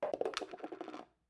描述：塑料壶的盖子掉下来了。用Samson C01录制的。
标签： 塑料
声道立体声